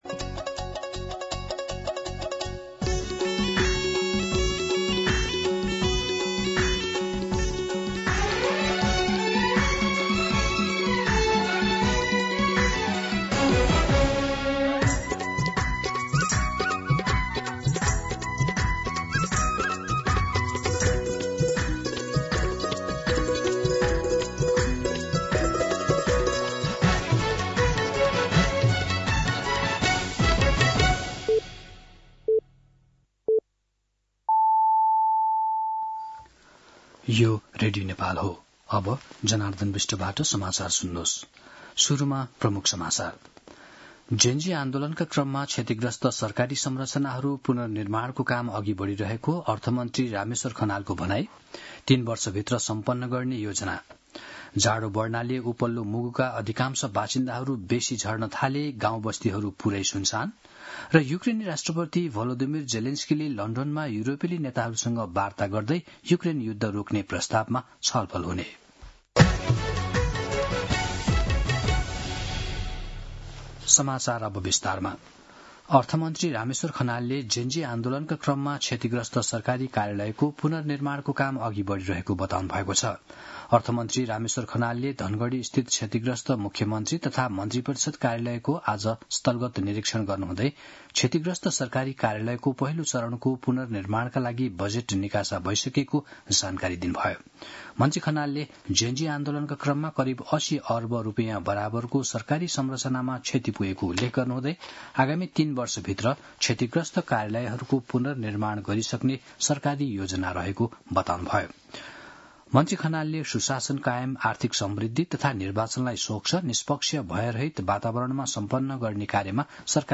दिउँसो ३ बजेको नेपाली समाचार : २२ मंसिर , २०८२